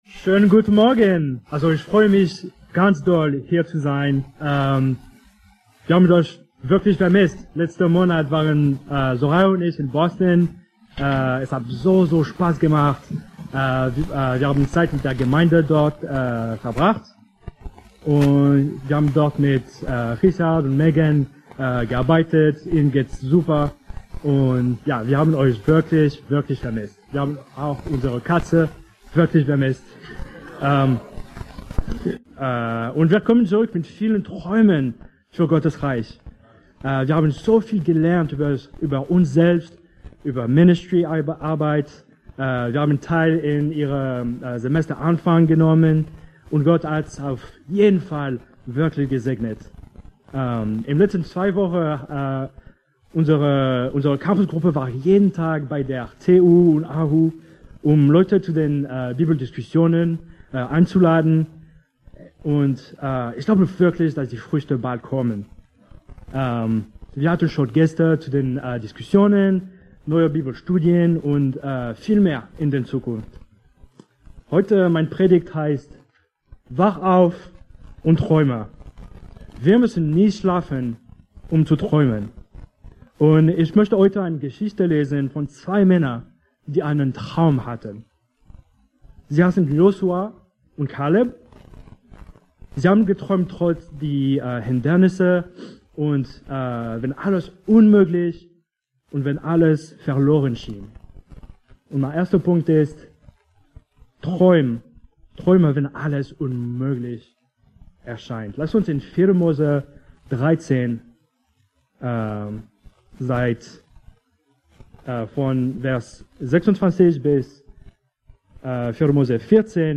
Wach auf und träume! ~ BGC Predigten Gottesdienst Podcast